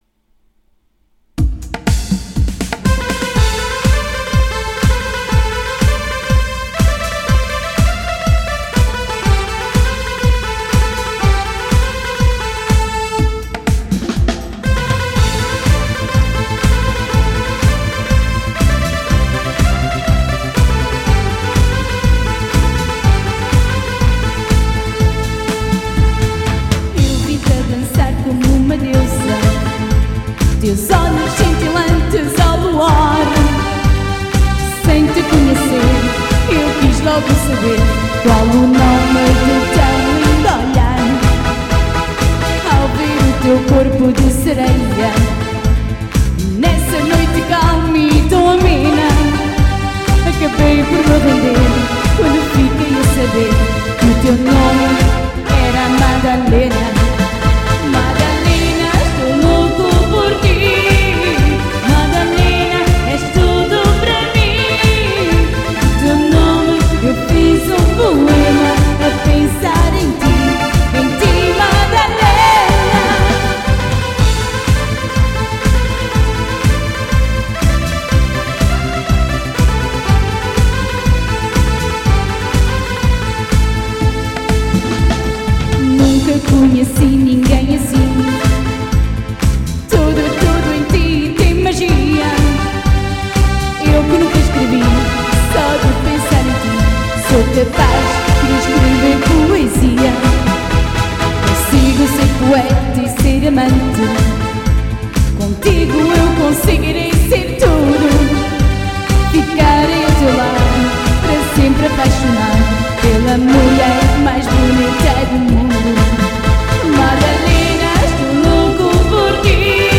Grupo de baile